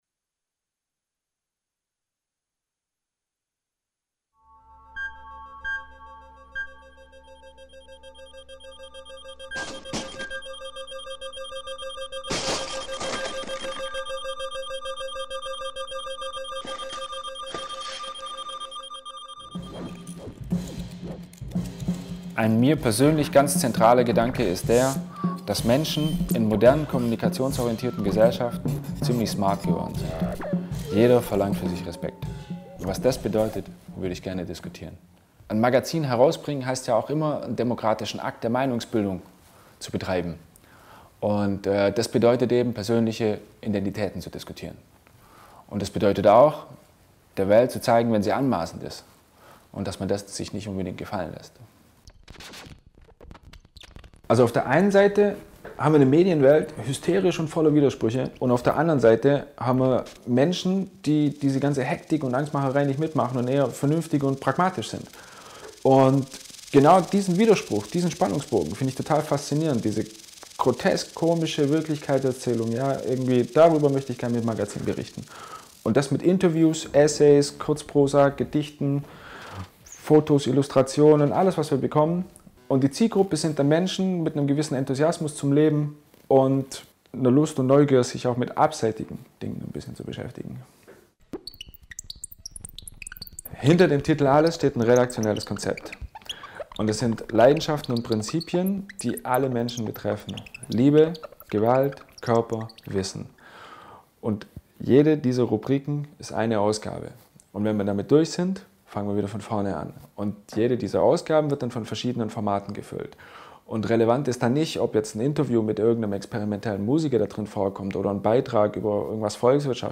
Des Weiteren werden teilweise Artikel und Lyrik vorgetragen und als Höreindruck der bisher erschienen Ausgaben aufbereitet.